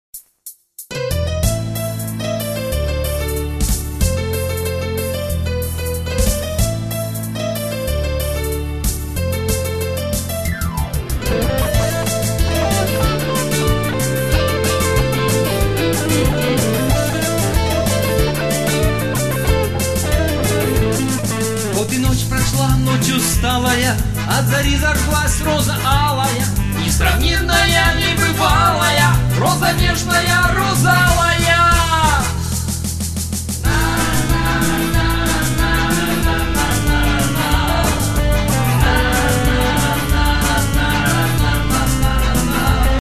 Демо-версии наших песен